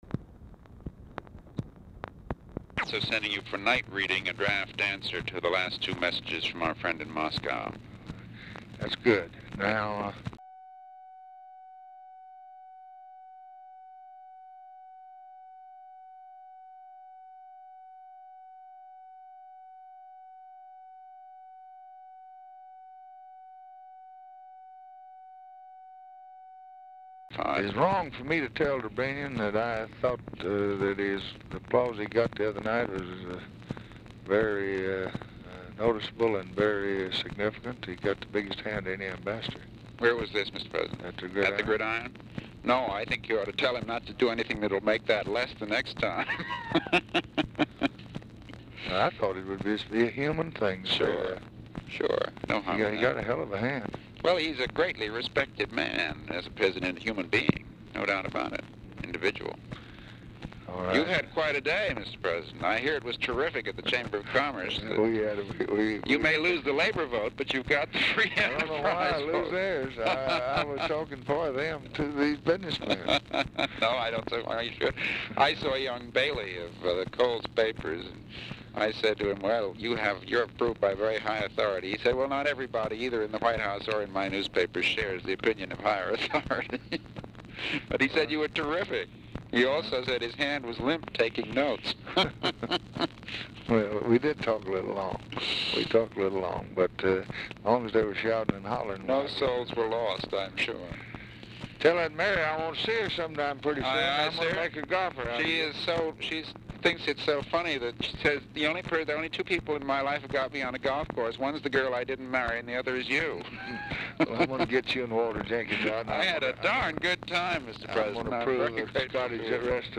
Telephone conversation # 3143, sound recording, LBJ and MCGEORGE BUNDY, 4/27/1964, 6:20PM | Discover LBJ
Format Dictation belt
Location Of Speaker 1 Oval Office or unknown location